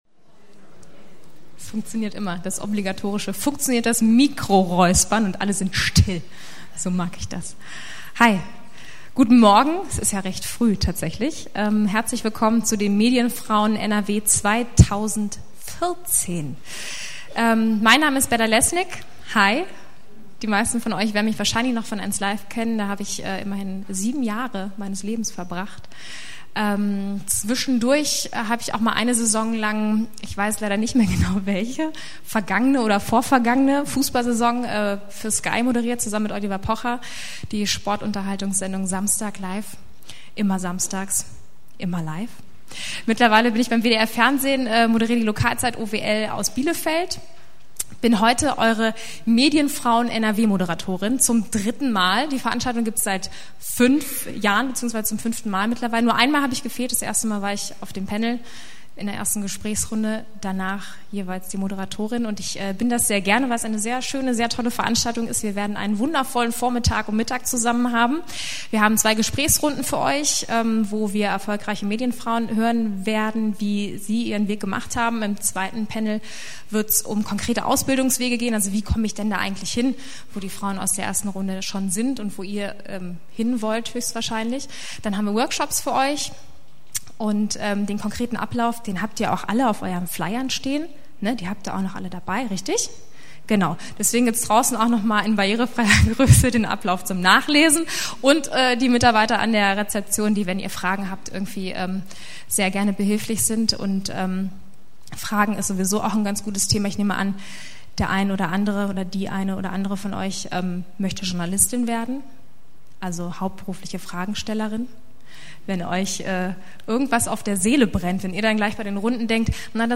MF_NRW_2014_Begruessung.mp3